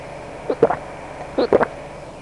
Drinking Water Sound Effect
Download a high-quality drinking water sound effect.
drinking-water.mp3